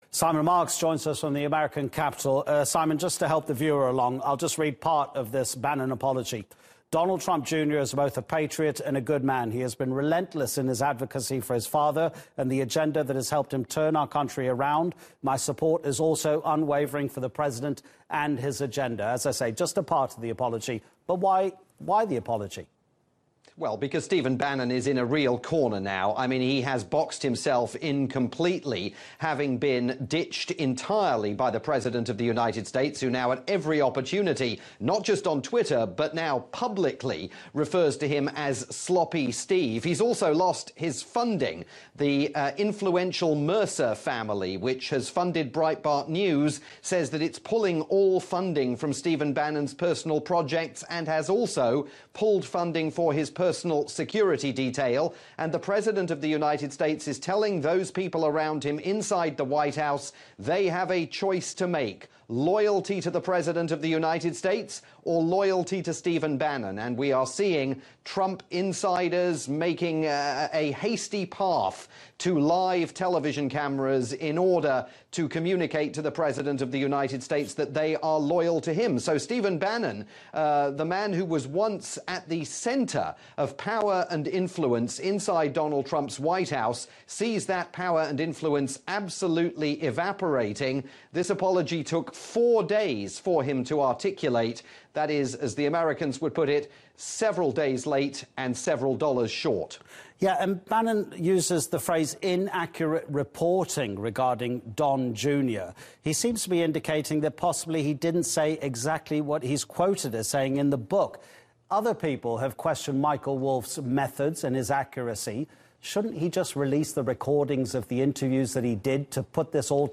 Via TRT World, Turkey's global news channel